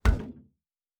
Metal Box Impact 2_5.wav